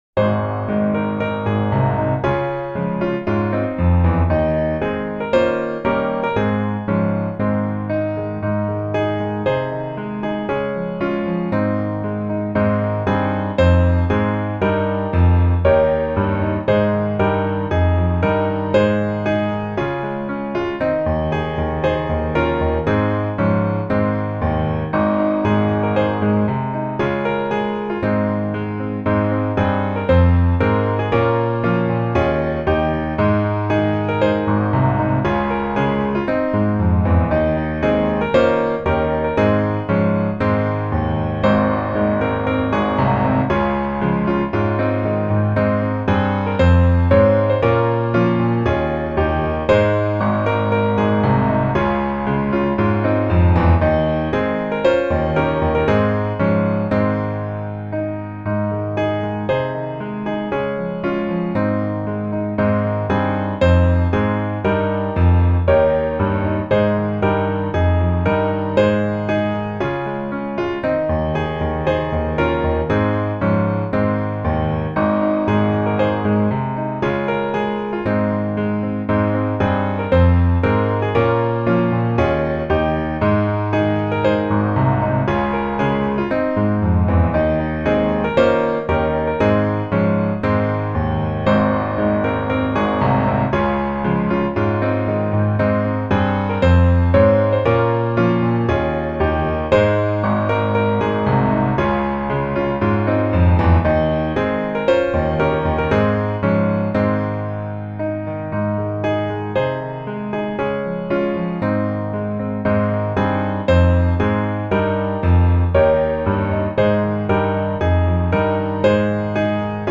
Ab Majeur
8.6.8.6. avec le refrain.